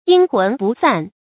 阴魂不散 yīn hún bù sàn 成语解释 比喻坏人、坏事虽已清除，但不良的影响还在起作用。